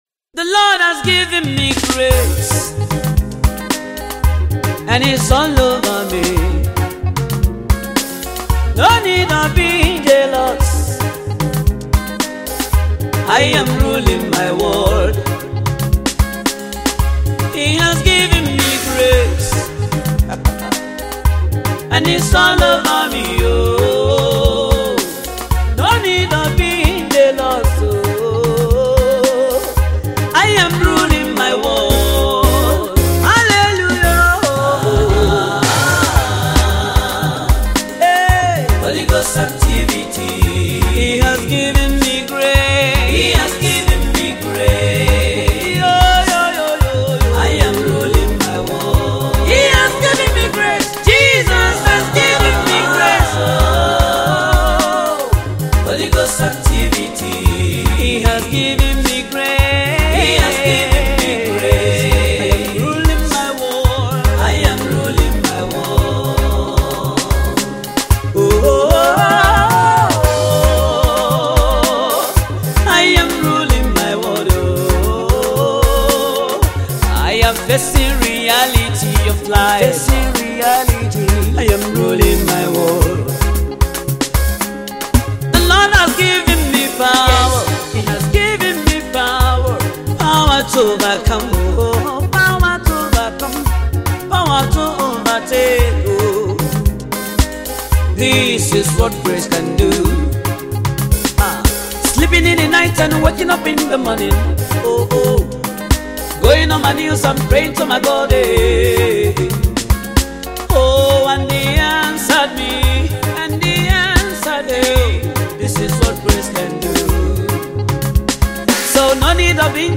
Igbo gospel